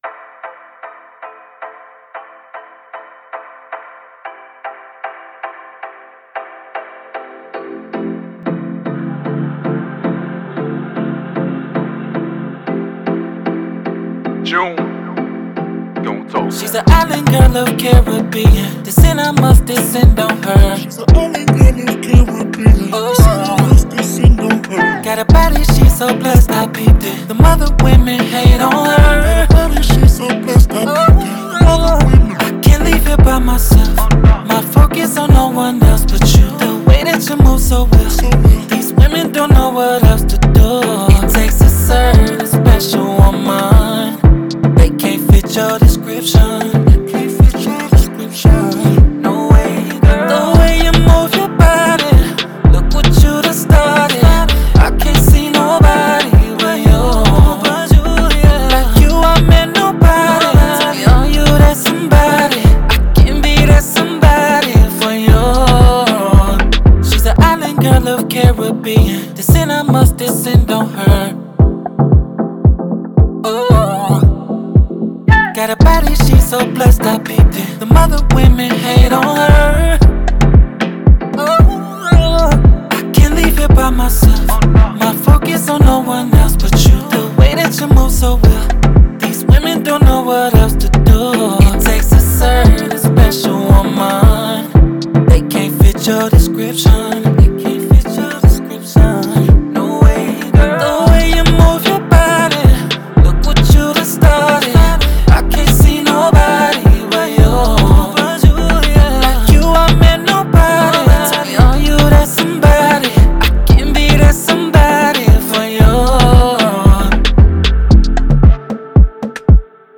R&B
A Minor